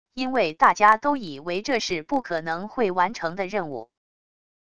因为大家都以为这是不可能会完成的任务wav音频生成系统WAV Audio Player